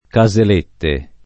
Caselette [ ka @ el % tte ]